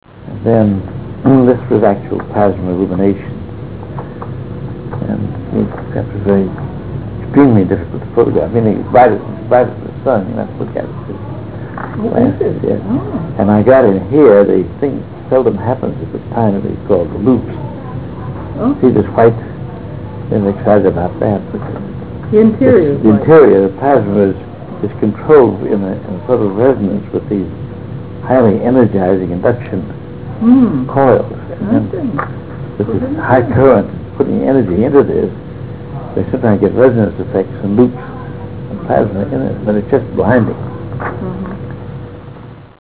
343Kb Ulaw Soundfile Hear Ansel Adams discuss this photo: [343Kb Ulaw Soundfile]